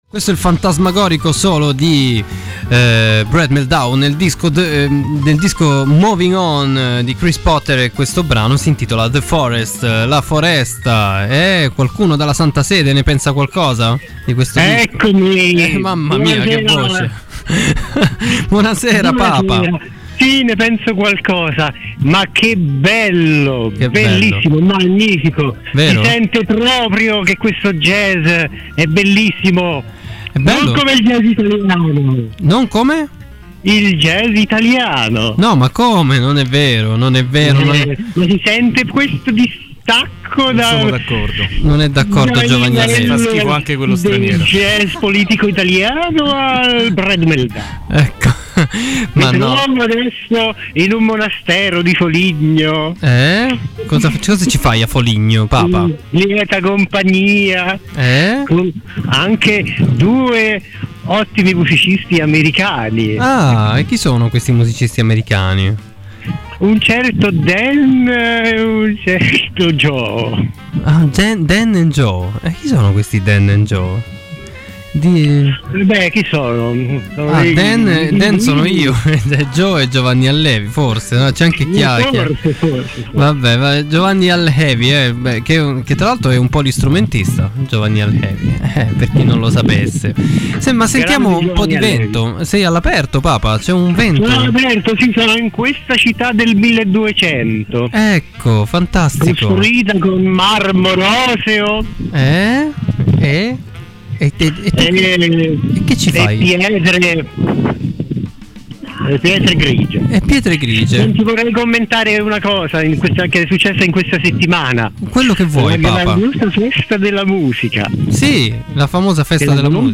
Sua Santità, il III Papa, irrompe negli studi di Radio Città Aperta, evocando la potente Maleficent!